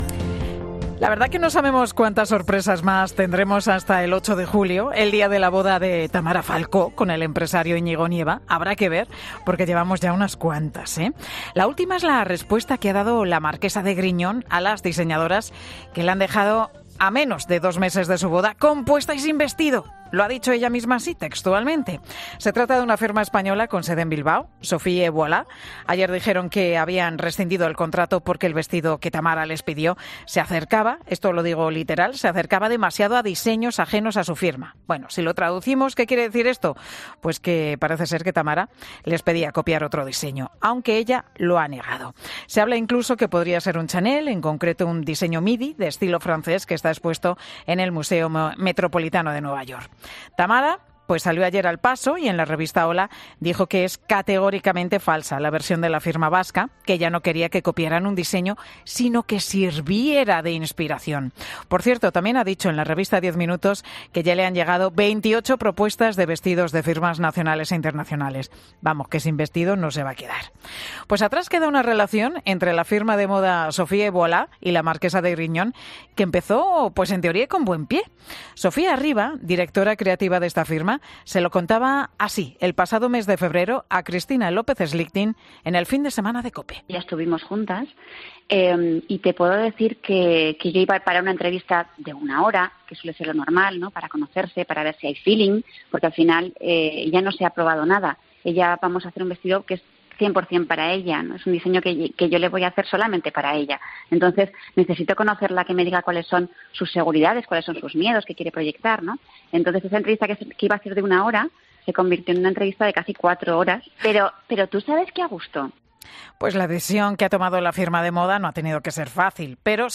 Analizamos el caso con dos expertas en 'Mediodía COPE'